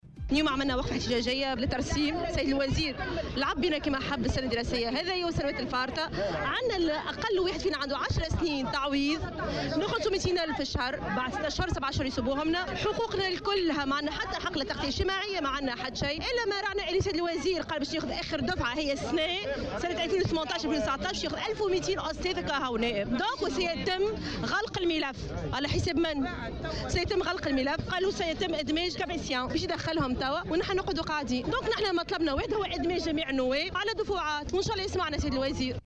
انتقدت النائب بمجلس الشعب، سامية عبو، خلال جلسة استماع لوزير التربية، حاتم بن سالم، تكليف شخص بمهمة صلب الوزارة صدر سابقا ضدّه حكم بالسجن في قضية فساد.